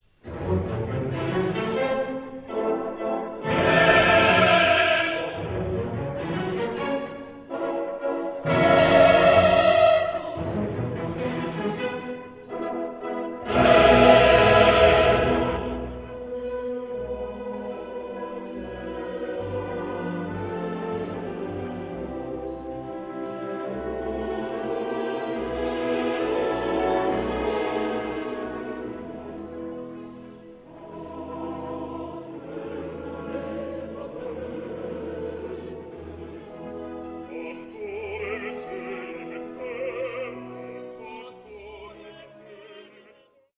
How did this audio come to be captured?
Here are some samples of great choral music, mostly own recordings made during concerts of the choirs named on this site.